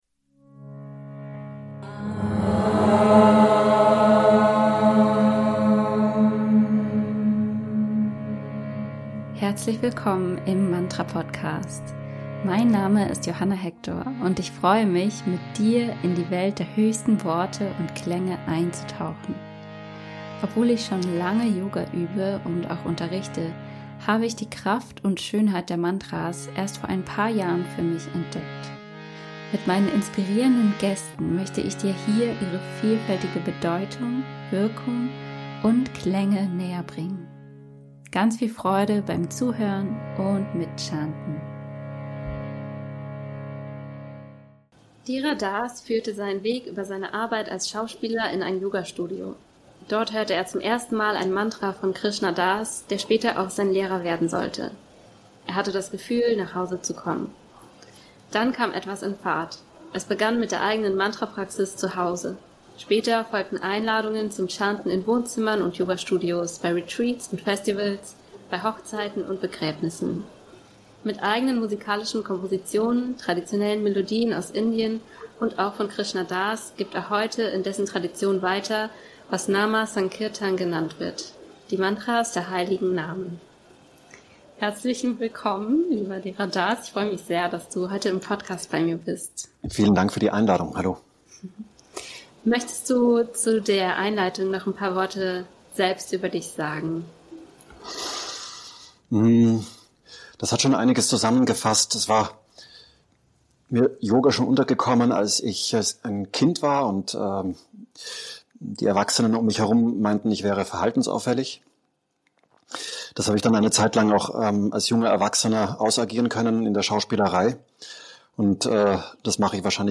Außerdem gibt es wie immer die einzigartige Version des Mantras live gespielt zu hören.